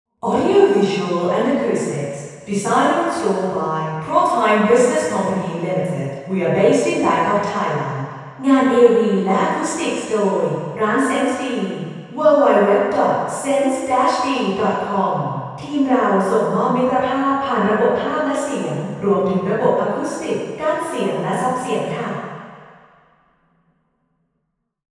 Room: Vethes Samosorn, Ministry of Foreign Affairs
Test Position 3: 16 m